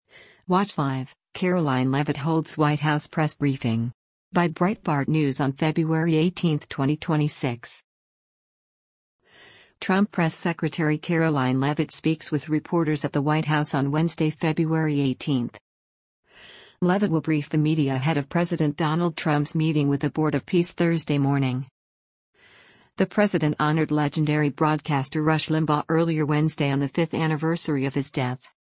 Trump Press Secretary Karoline Leavitt speaks with reporters at the White House on Wednesday, February 18.